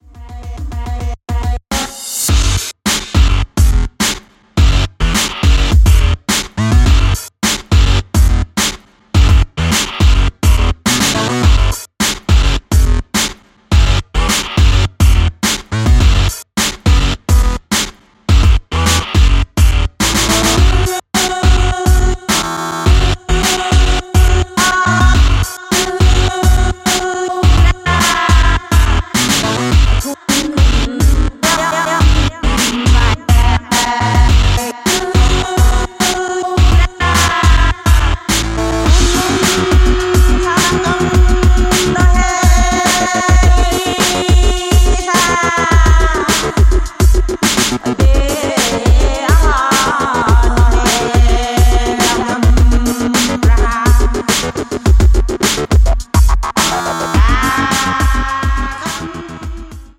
流派: 流行乐